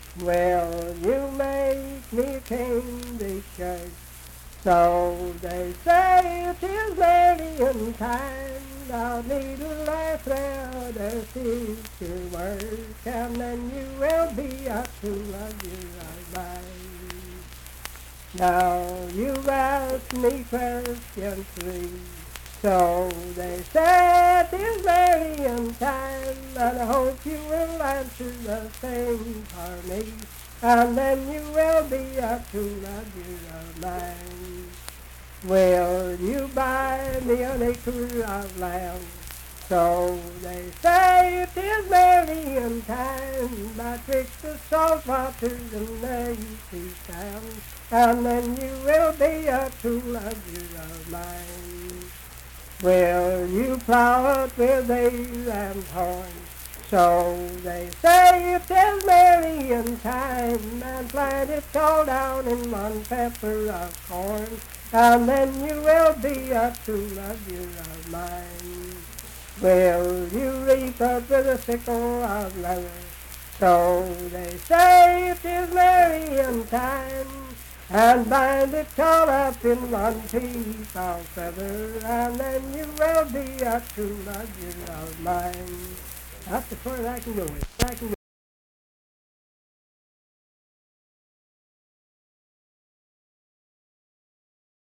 Unaccompanied vocal music
Verse-refrain 5(4w/R).
Performed in Ivydale, Clay County, WV.
Love and Lovers, Dance, Game, and Party Songs
Voice (sung)